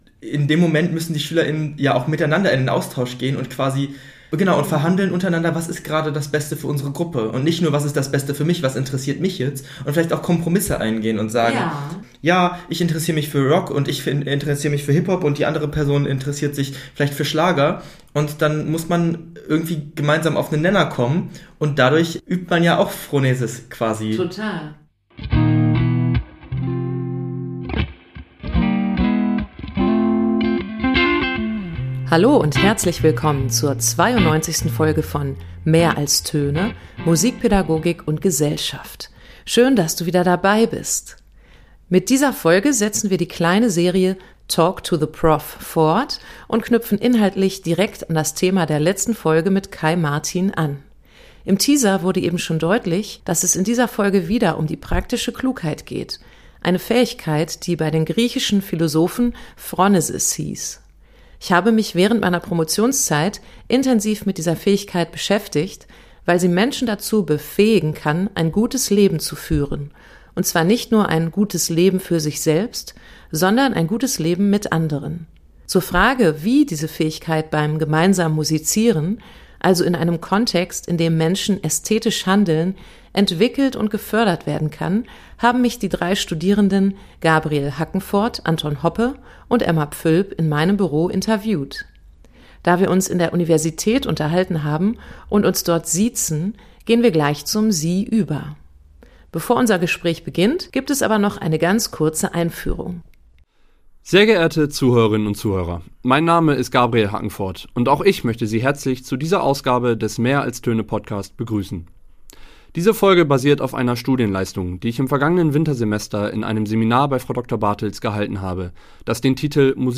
In der Serie geht es nicht nur darum, dass Studierende Professor:innen interviewen, sondern auch um Musik und das „gute Leben“. In dieser Folge geht es noch einmal um die phrónesis – die praktische Klugheit, die immer dann relevant wird, wenn Menschen mit anderen handeln wollen. Da das Gespräch in der Prüfungszeit stattfand, diskutiert die Gruppe am Anfang das Thema Bewertung künstlerischer Leistungen in Prüfungen und im Musikunterricht.